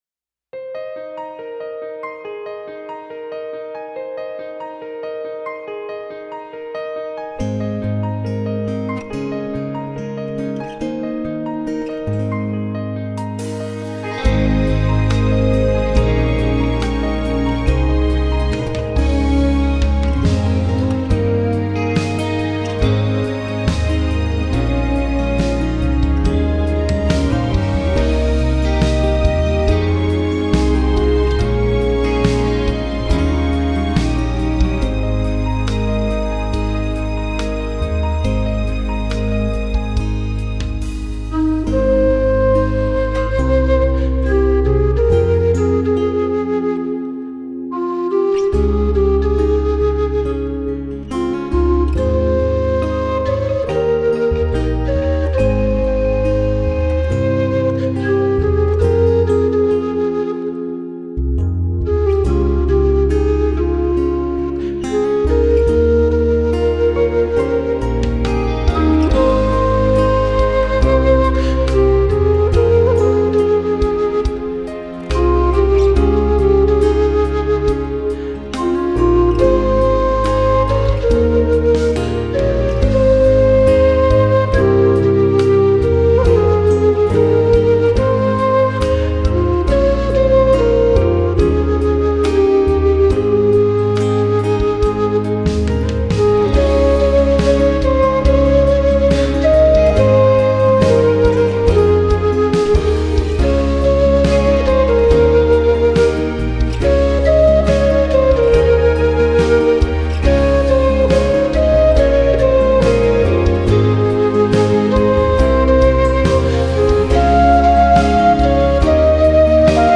36 사랑하고 계시네 (Low Whistle